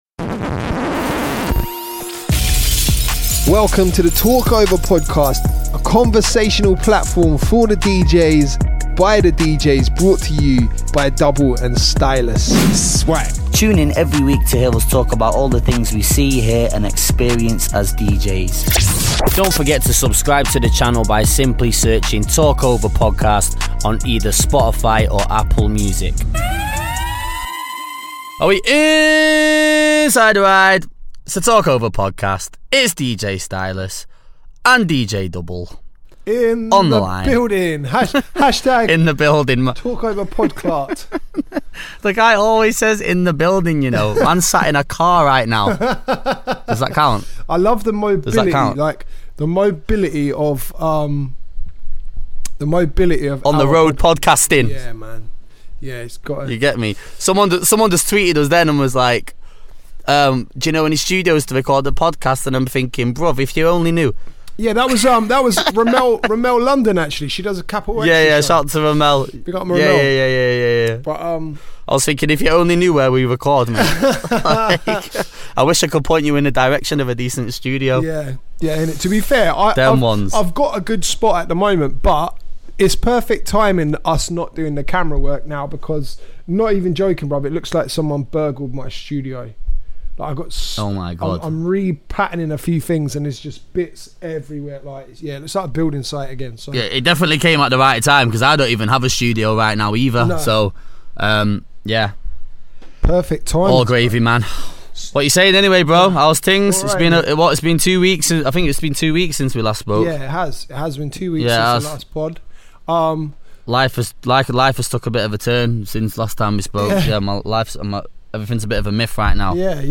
We discuss relevant topics, giving our unfiltered & honest opinions about anything that comes up in conversation!